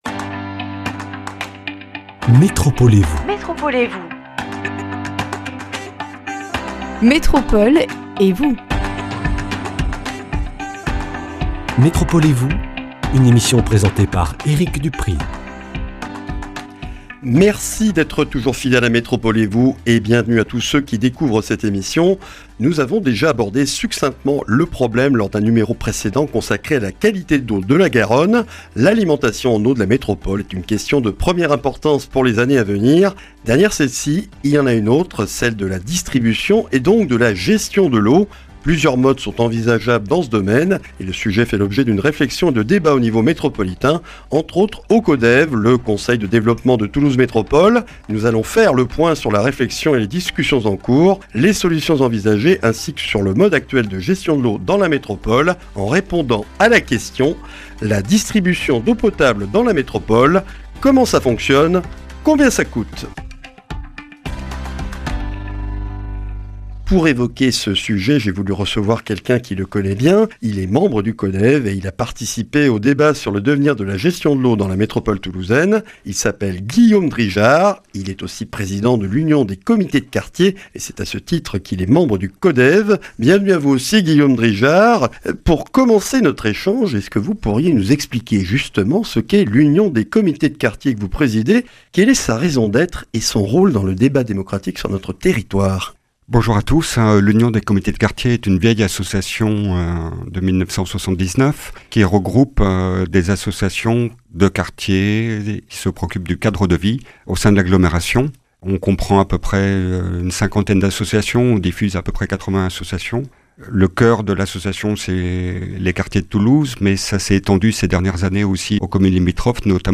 Une émission